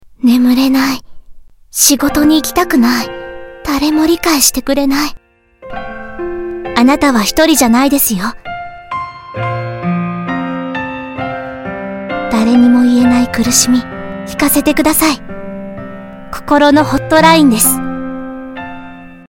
日语青年娓娓道来 、积极向上 、时尚活力 、神秘性感 、亲切甜美 、素人 、女专题片 、宣传片 、广告 、飞碟说/MG 、课件PPT 、工程介绍 、绘本故事 、动漫动画游戏影视 、旅游导览 、微电影旁白/内心独白 、看稿报价日语T6-女1 demo3 娓娓道来|积极向上|时尚活力|神秘性感|亲切甜美|素人